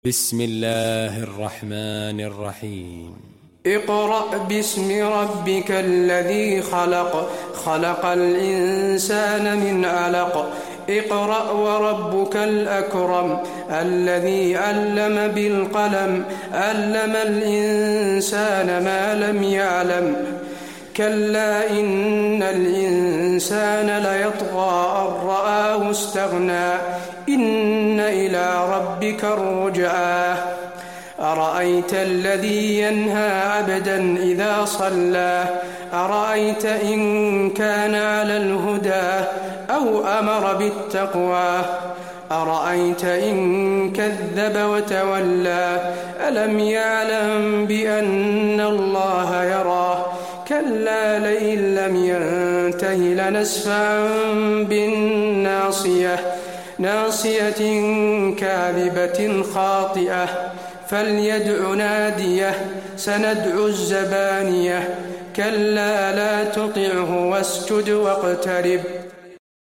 المكان: المسجد النبوي العلق